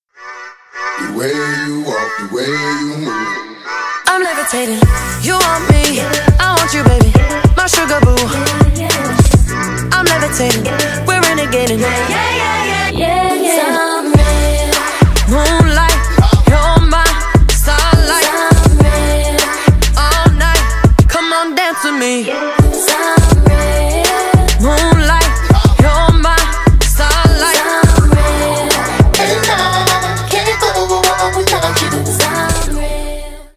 Ремикс
клубные # громкие